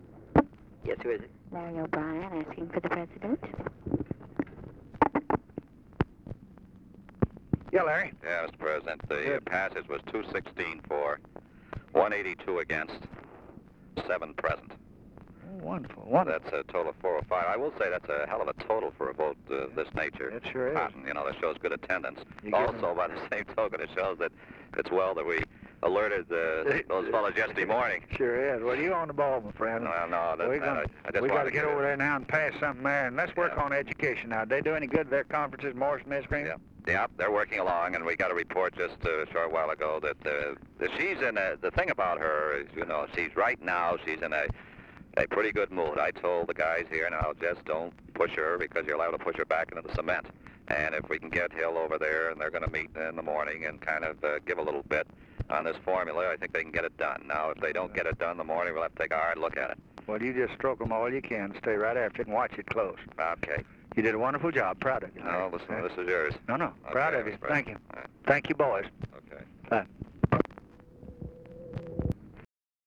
Conversation with LARRY O'BRIEN, December 4, 1963
Secret White House Tapes